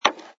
sfx_put_down_bottle07.wav